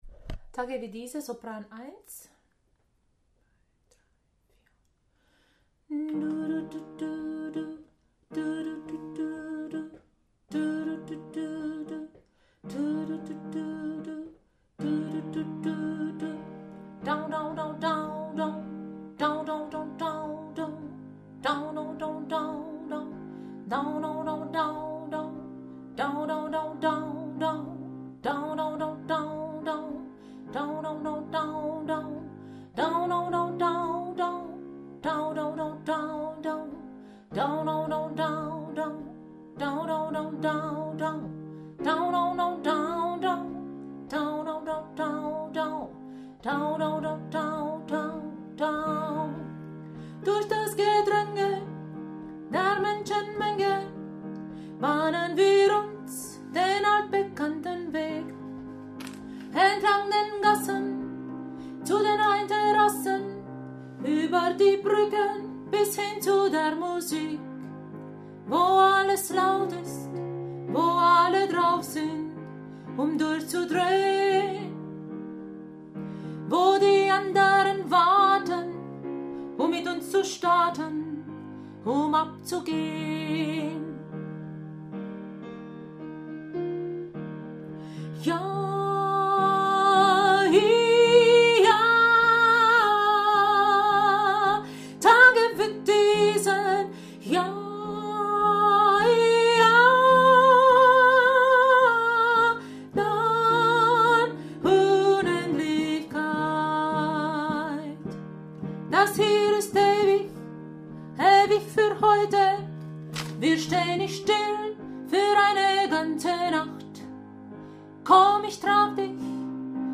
Tage wie diese – Sopran1